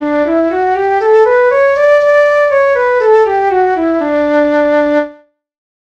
ドレミファソラシドのサンプル音源を元にピッチ修正したファイルのサンプルです。
長２度上げ（+2半音）：Dmajorのスケールのサウンド
DmajorScale.mp3